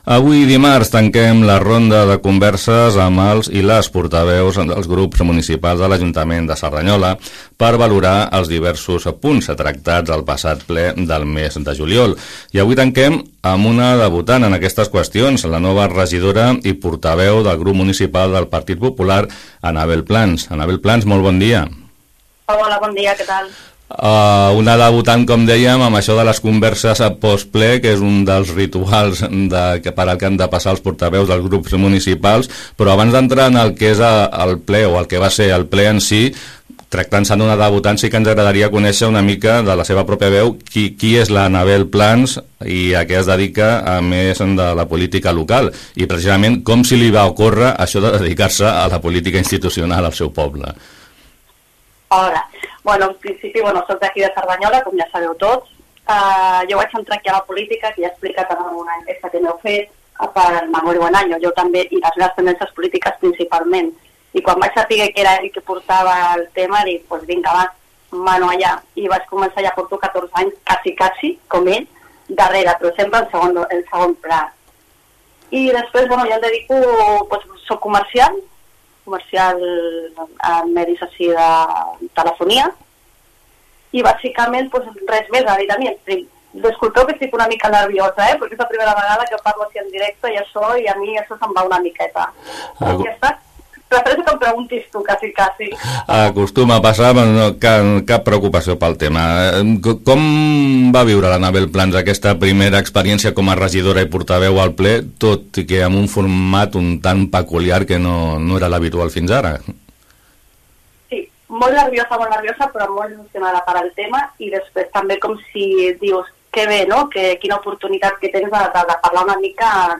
Entrevista-Anabel-Plans-PP-Ple-juliol.mp3